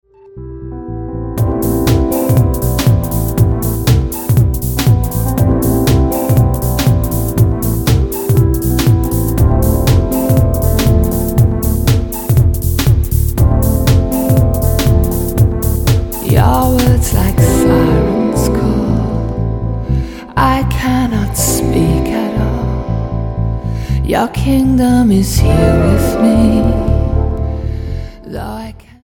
Ambient/Meditational